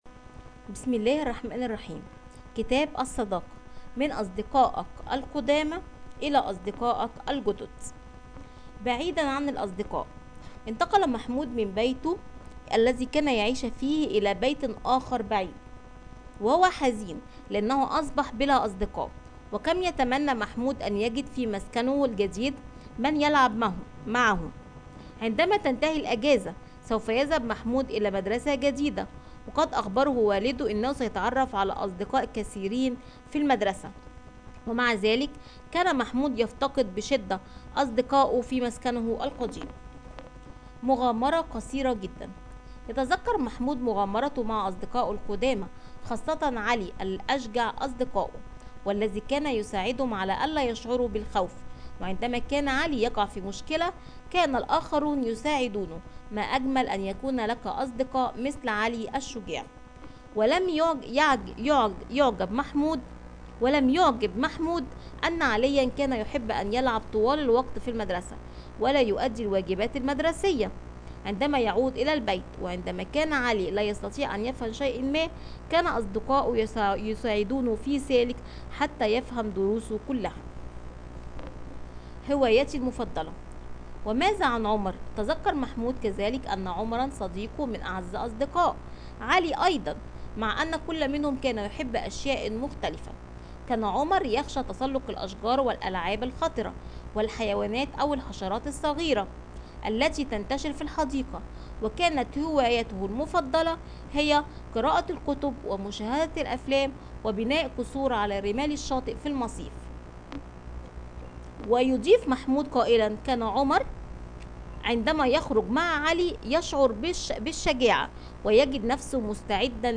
Sadakat – Arapça Sesli Hikayeler
Sadakat-arapca-sesli-hikayeler.mp3